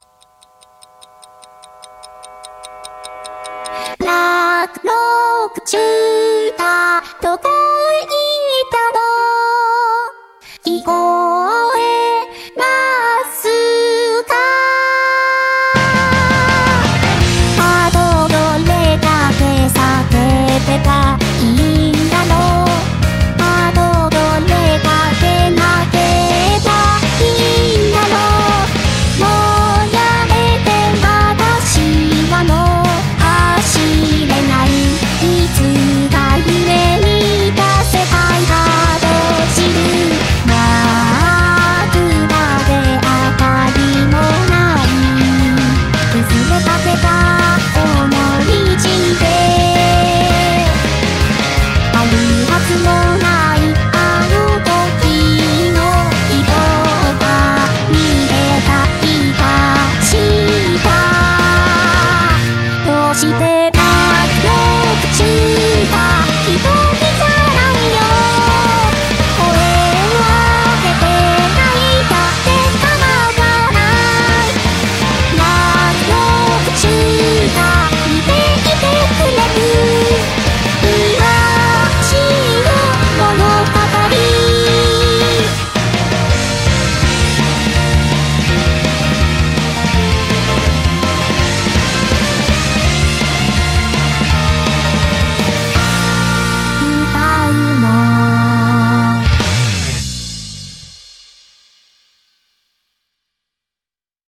BPM139-165
Audio QualityMusic Cut